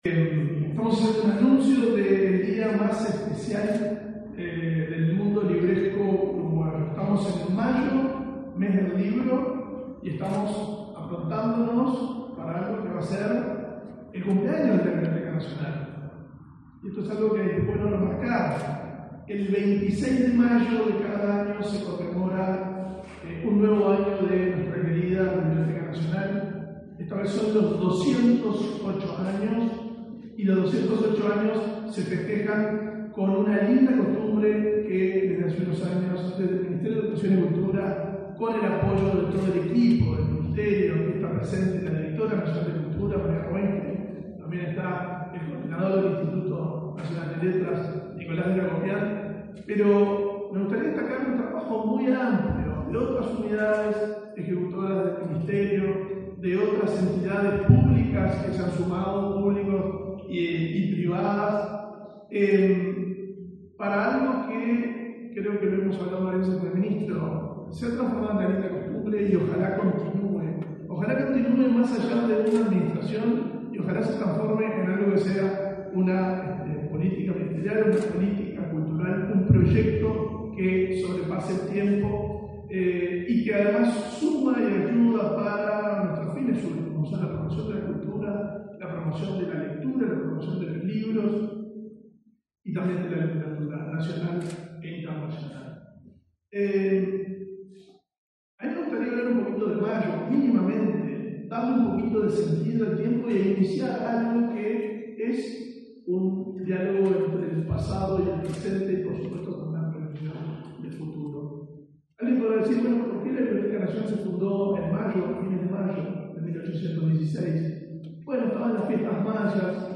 Palabras de autoridades en acto en la Biblioteca Nacional
Palabras de autoridades en acto en la Biblioteca Nacional 08/05/2024 Compartir Facebook X Copiar enlace WhatsApp LinkedIn Este miércoles 8, el director de la Biblioteca Nacional, Valentín Trujillo, y el ministro de Educación y Cultura, Pablo da Silveira, participaron en el lanzamiento del Día Nacional del Libro y un nuevo aniversario de la Biblioteca Nacional.